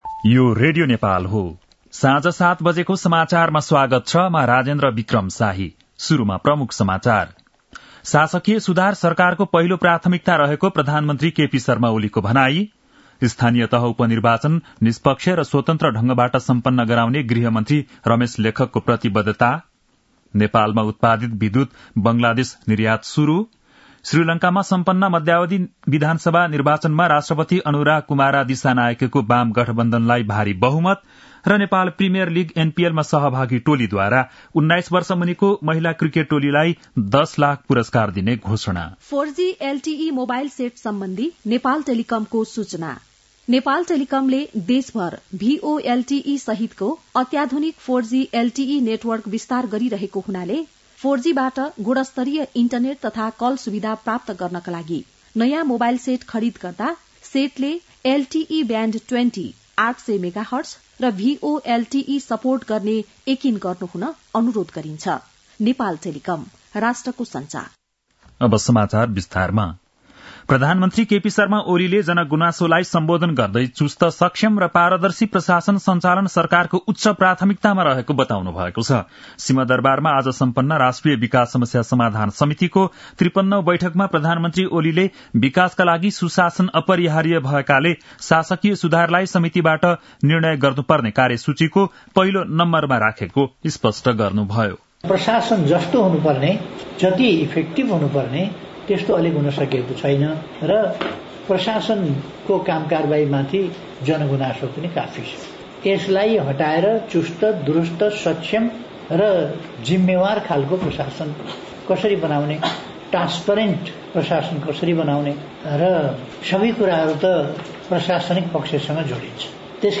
बेलुकी ७ बजेको नेपाली समाचार : १ मंसिर , २०८१
7-pm-nepali-news-7-30.mp3